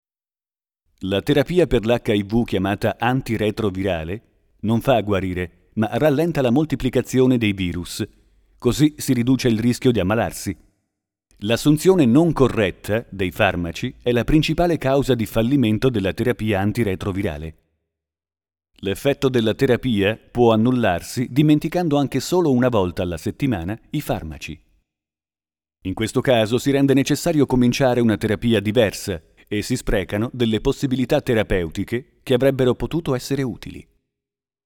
Kein Dialekt
Sprechprobe: eLearning (Muttersprache):
Speaker radio-tv-multimedia Soundesign - editing audio